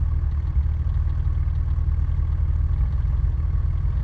rr3-assets/files/.depot/audio/Vehicles/f6_01/f6_01_idle.wav
f6_01_idle.wav